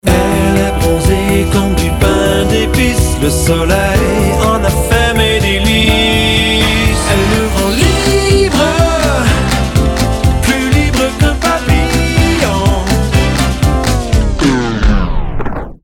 For this distinguished, enchanting and elegant album